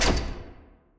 door-open-remote.wav